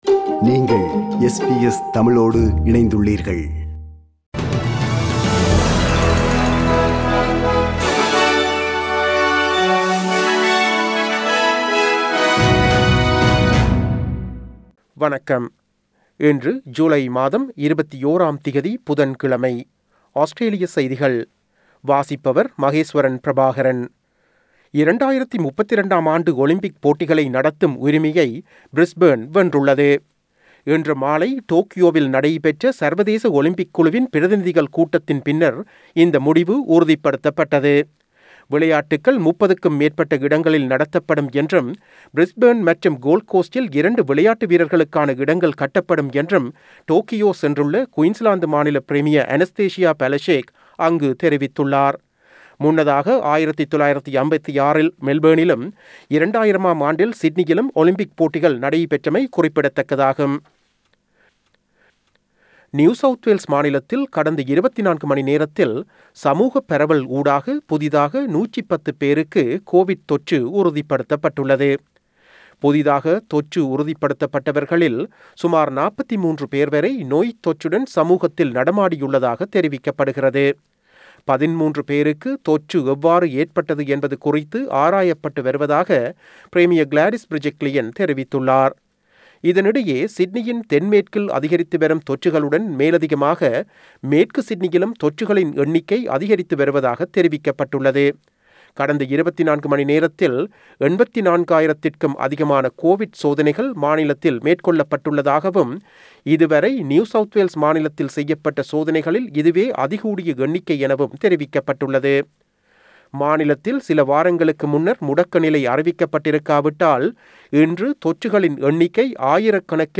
Australian news bulletin for Wednesday 21 July 2021.